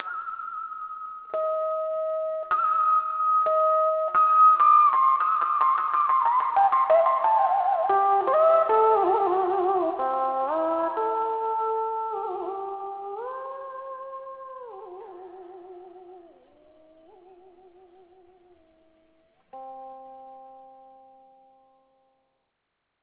The instrument's single string stretchs over a trapezoidal wood soundboard.
Subtle ornamentations, glissandi sounds, and imitation of the human voice could be produced by simultaneously plucking the string and pulling the ancient whammy bar.